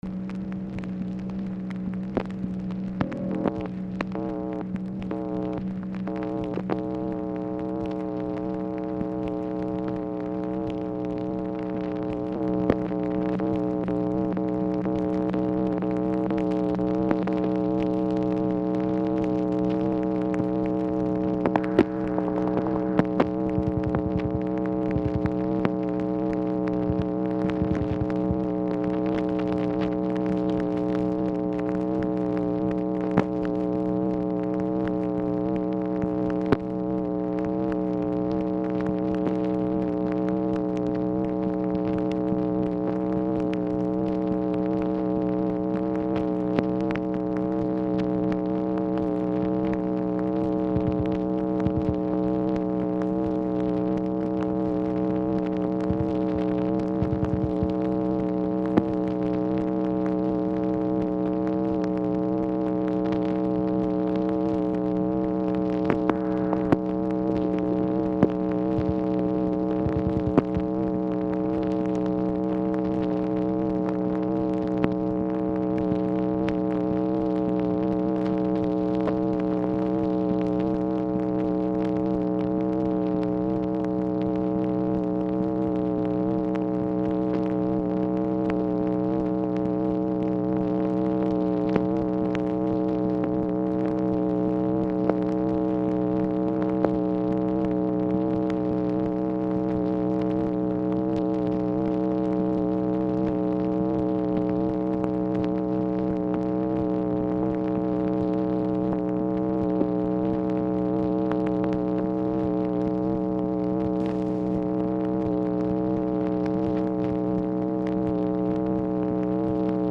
Telephone conversation # 8394, sound recording, MACHINE NOISE, 7/26/1965, time unknown | Discover LBJ
Format Dictation belt